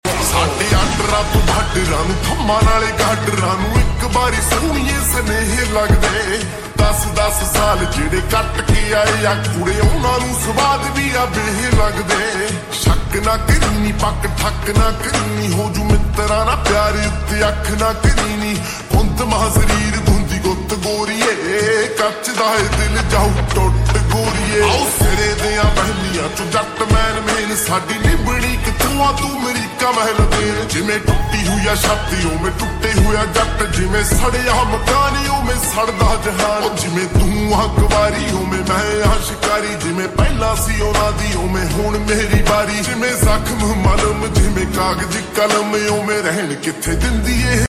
Fox 😬 Sound Effects Free Download